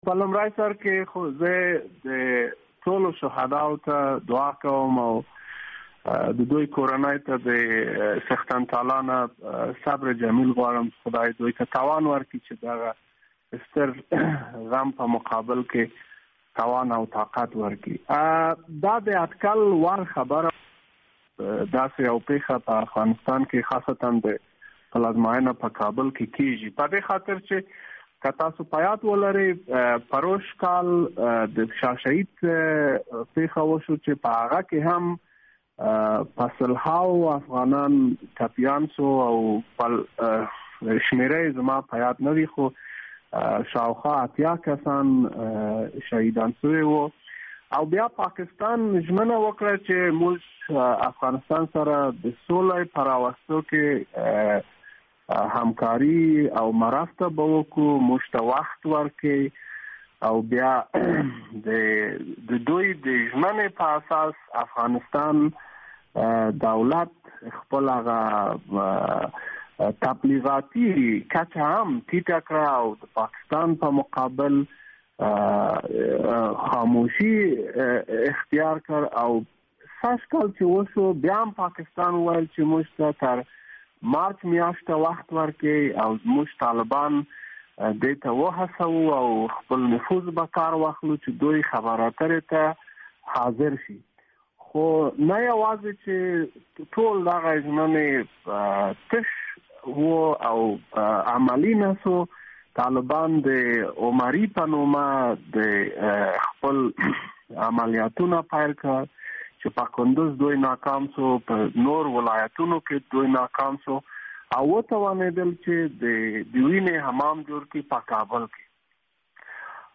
مرکه
د ملي امنيت د پخواني مشر امرالله صالح سره مرکه: